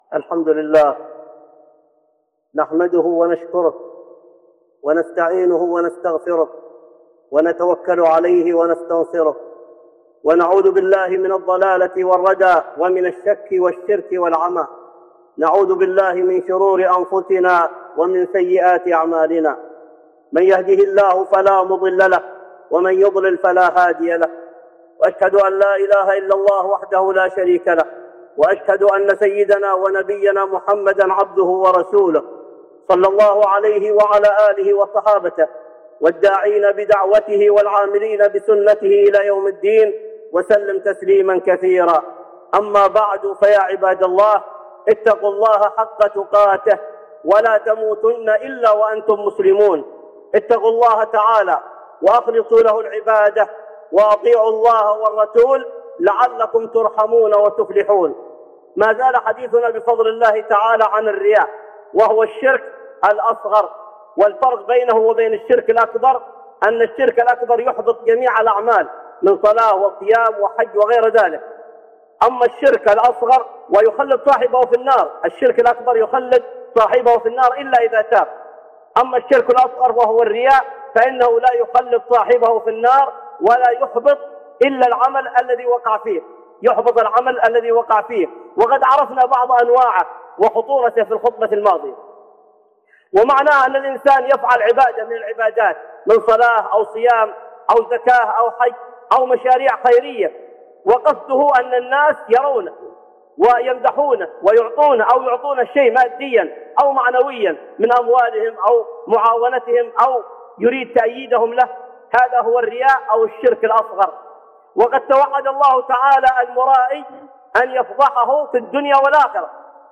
(خطبة جمعة) الرياء وثمرات الاخلاص